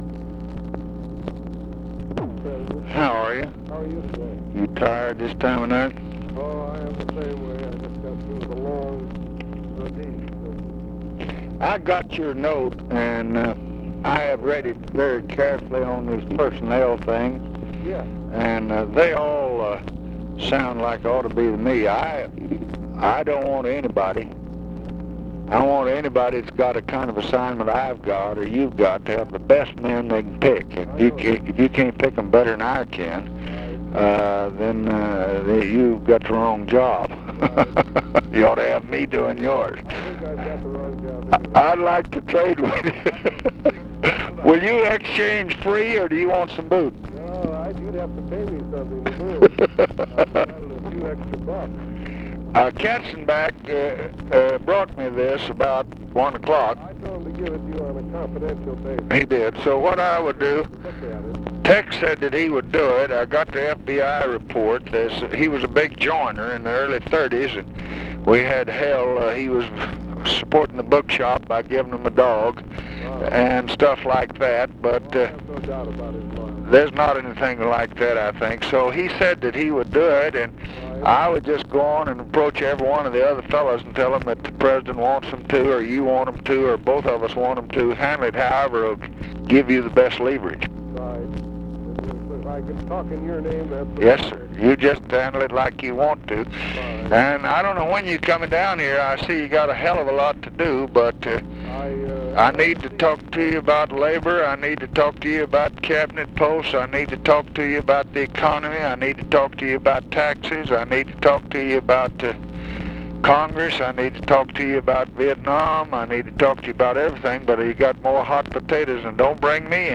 Conversation with ARTHUR GOLDBERG, December 13, 1966
Secret White House Tapes